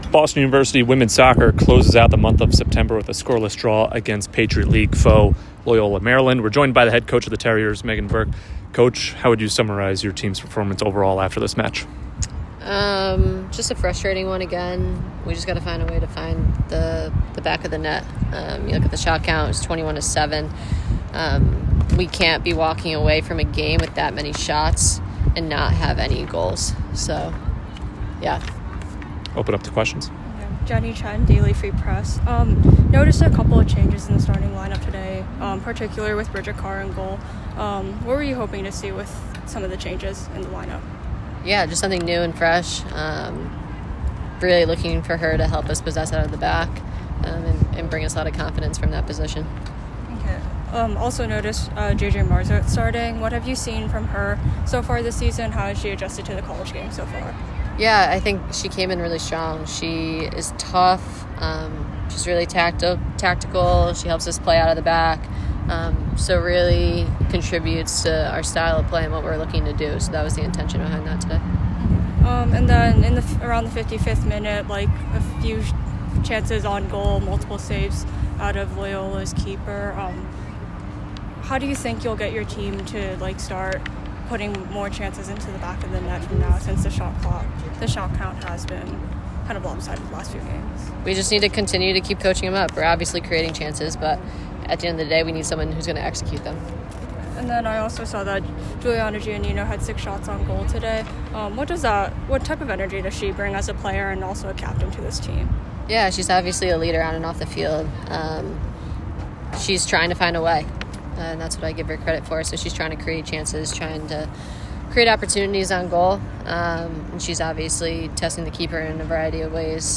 Loyola Maryland Postgame Interview
WSOC_Loyola_Postgame.mp3